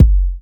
• Raw Urban Kick One Shot F# Key 242.wav
Royality free kick one shot tuned to the F# note. Loudest frequency: 121Hz
raw-urban-kick-one-shot-f-sharp-key-242-dIq.wav